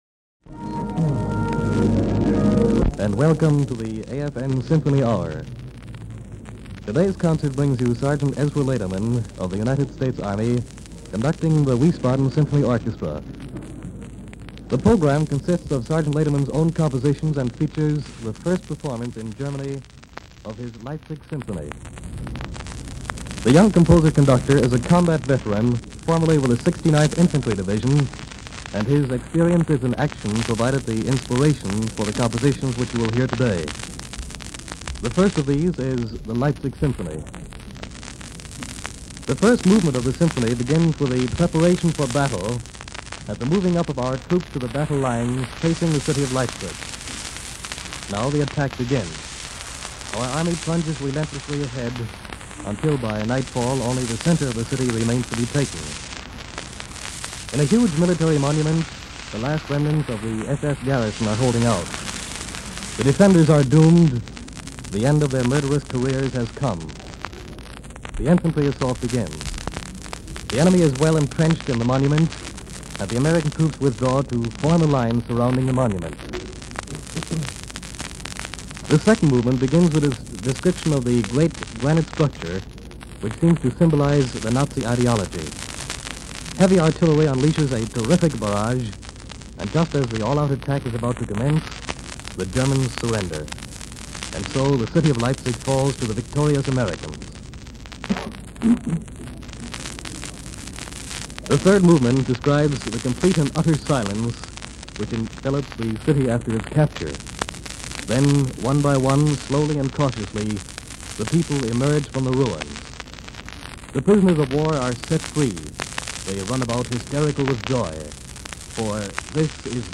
by Ezra Laderman | Live from Leipzig Germany in the Wiesbaden Symphony Hall
01-Leipzig-Symphony-Radio-Introduction.mp3